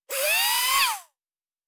pgs/Assets/Audio/Sci-Fi Sounds/Mechanical/Servo Small 1_1.wav at master
Servo Small 1_1.wav